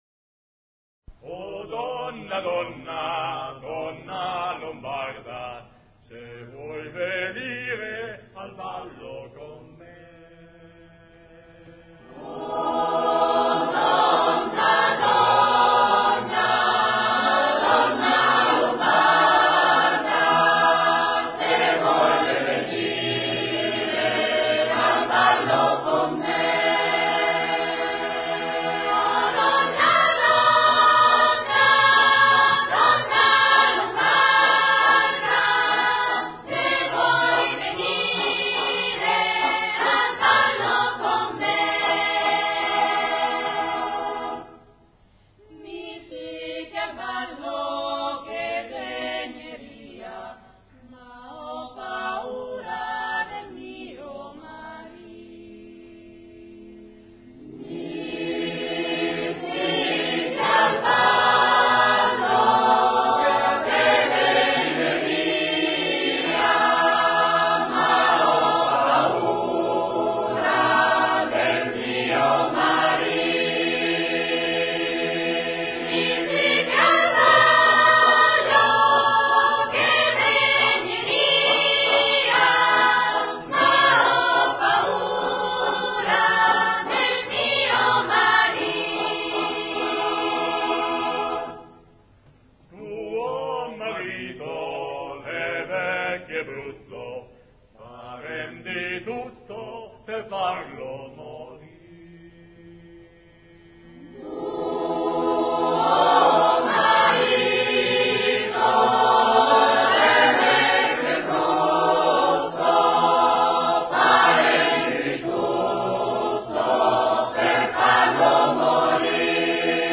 [ voci miste ]